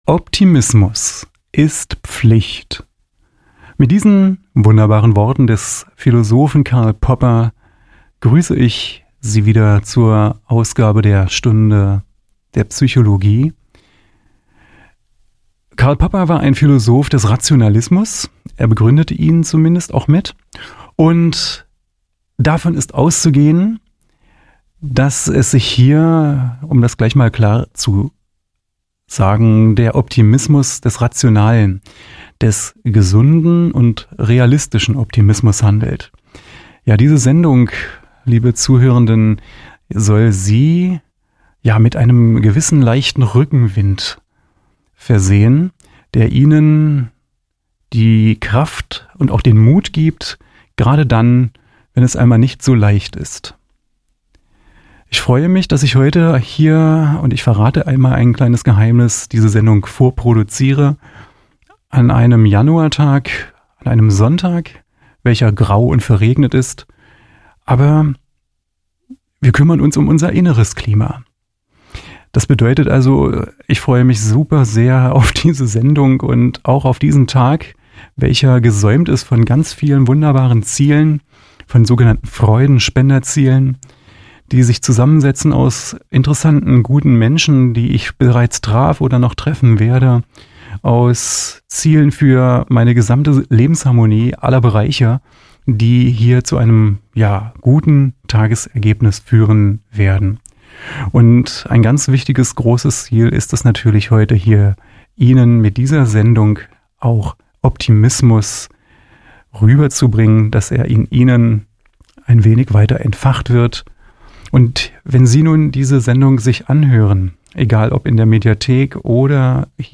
Soziale Kompetenzen sind die Voraussetzung für die Problembewältigung mit sich selbst und anderen sowie für eine gesunde Persönlichkeits- und Gesellschaftsentwicklung. Darum geht es in Vorträgen und Gesprächen